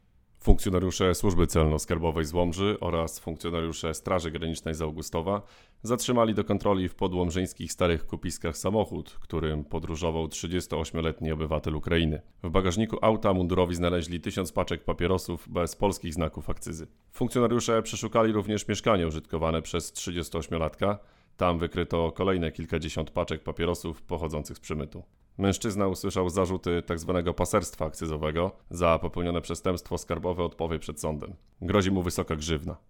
KAS i SG zabezpieczyły nielegalne papierosy (wypowiedź mł. rew.